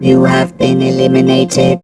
rick_kill_vo_04.wav